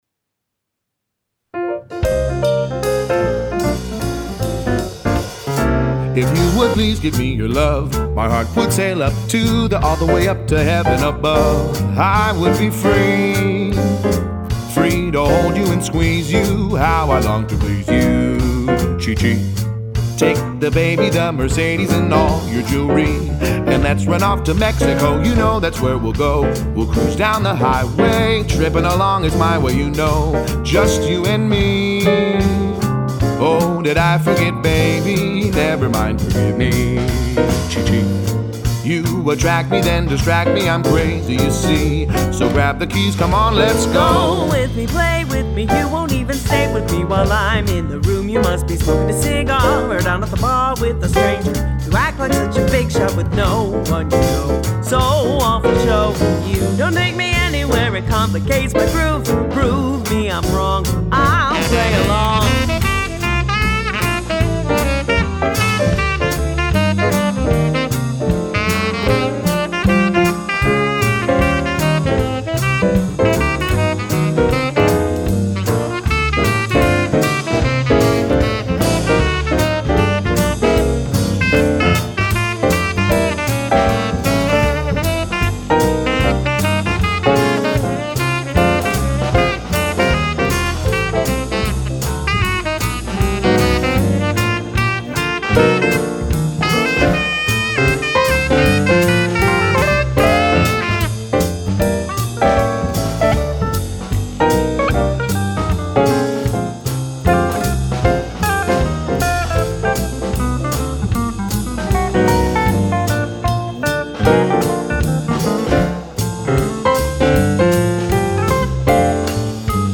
this duet was inspired by two Charlie Parker blues tunes